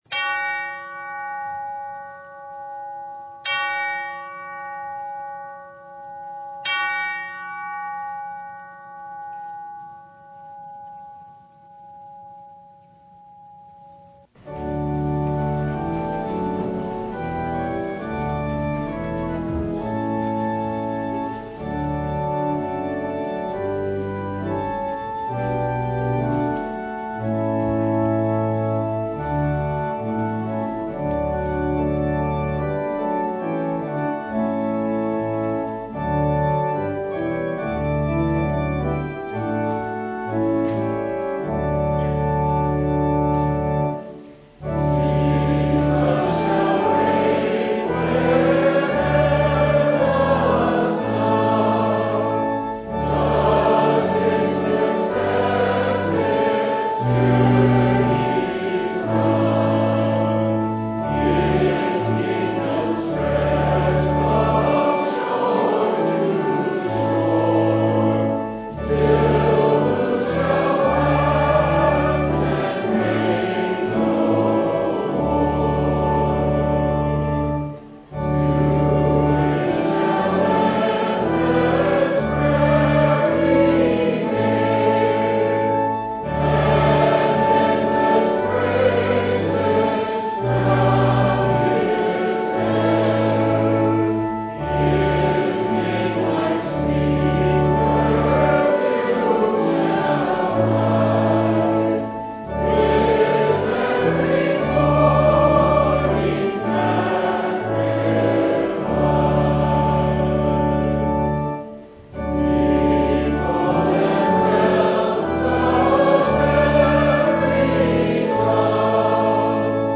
Matthew 15:21-28 Service Type: Sunday “Great is your faith!”